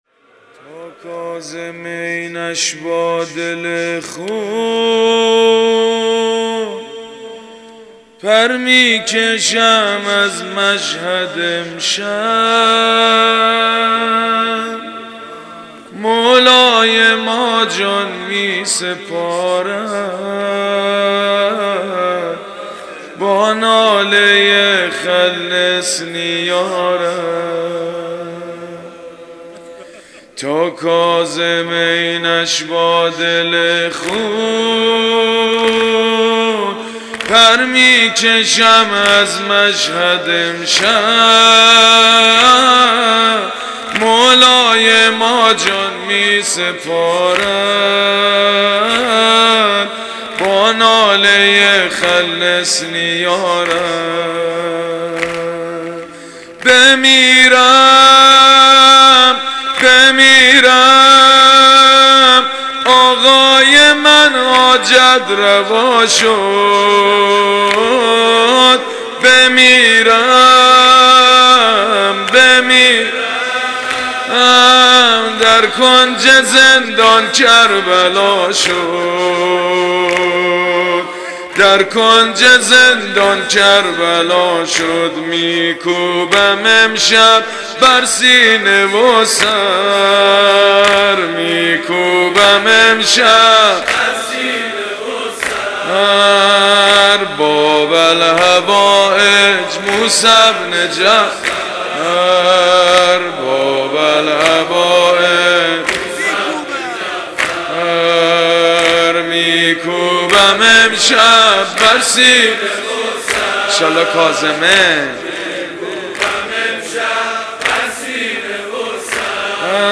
قالب : زمینه